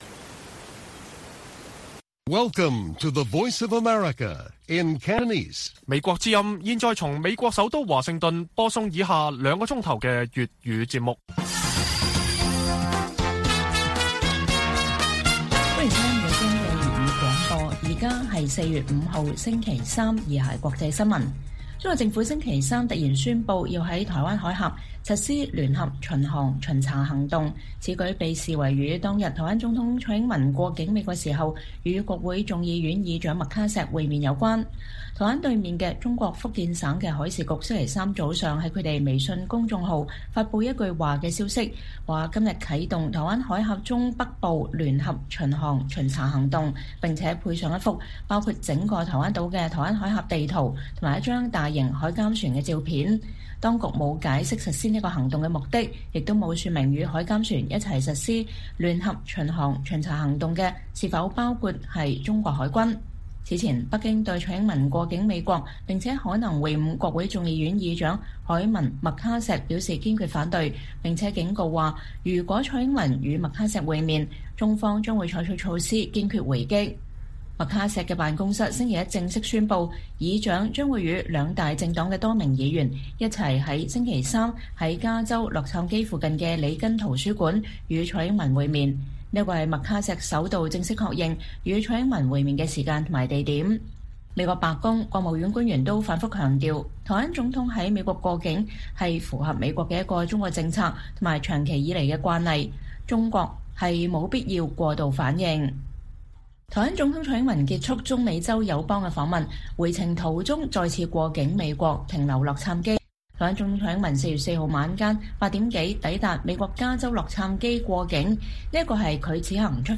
粵語新聞 晚上9-10點: 蔡英文會晤麥卡錫，究竟是美台雙贏還是美中撕破臉？